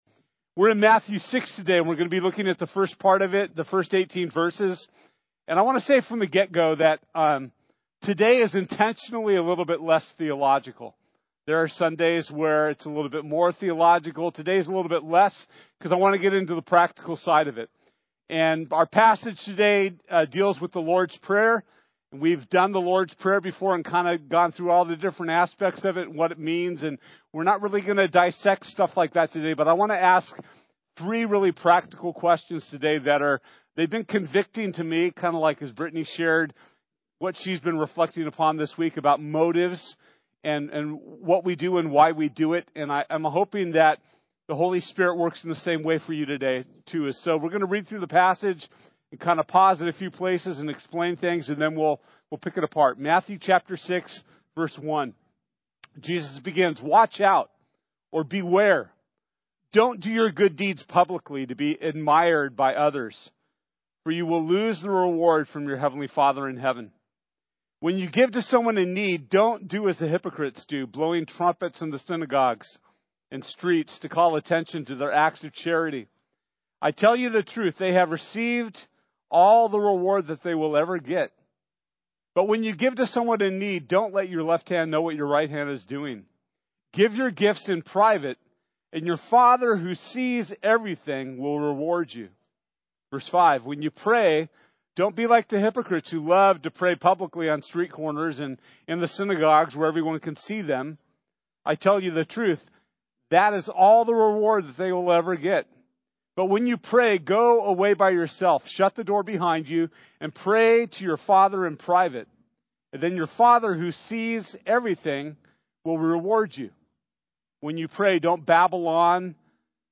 Matthew 6:1-18 Service Type: Sunday This week’s teaching is for you investors!